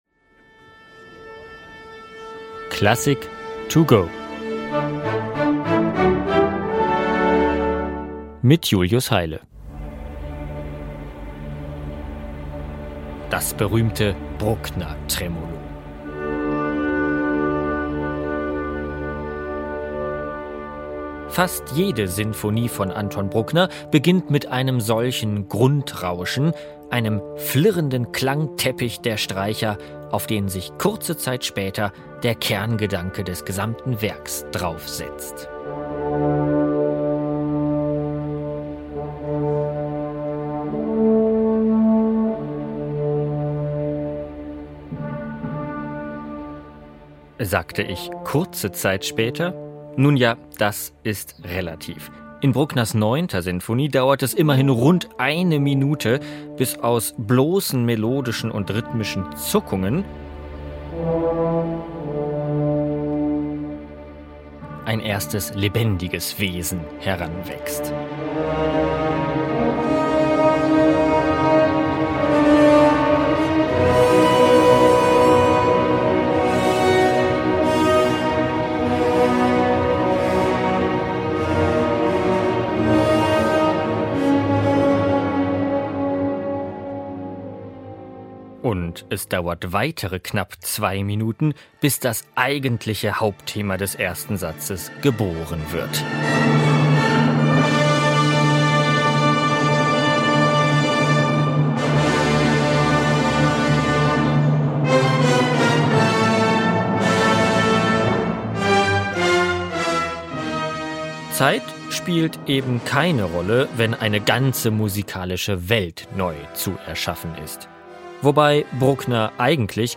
Werkeinführung